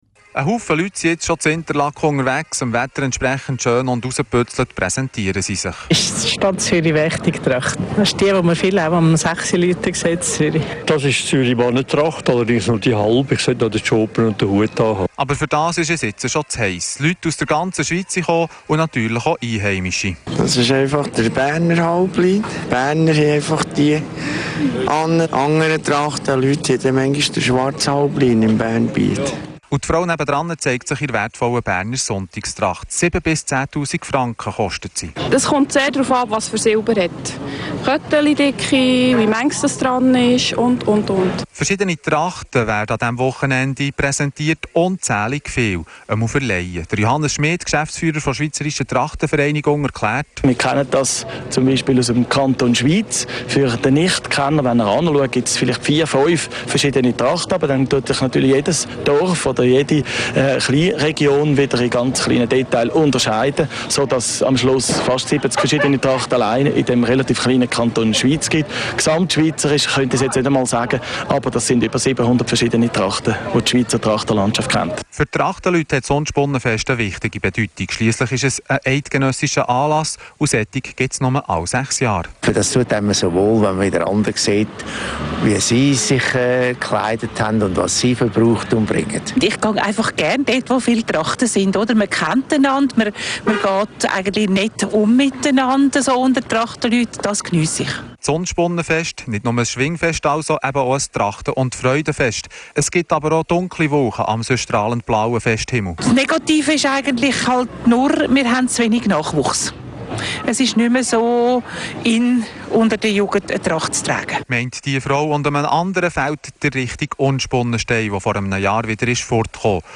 In diesem Jahr fand wieder einmal das traditionelle Schweizer Trachten- und Alphirtenfest in Unspunnen bei Interlaken statt.